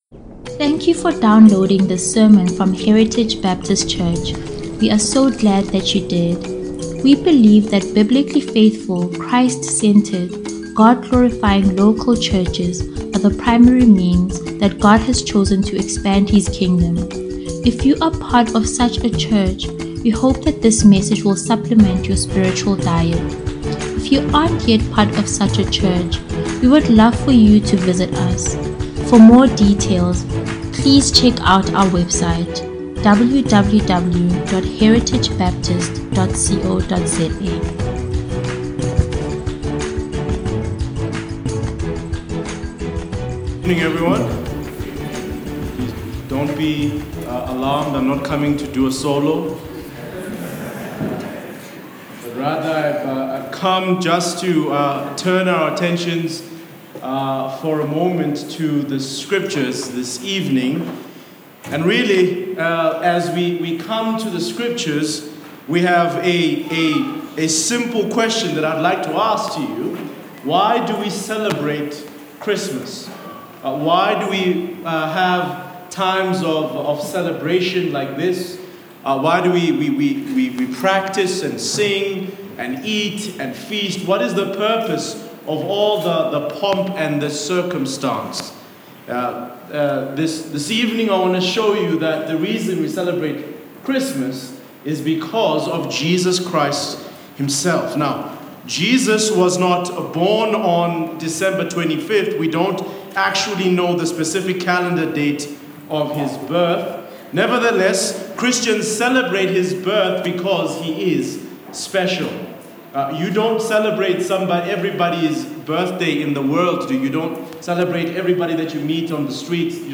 Heritage Sunday Sermons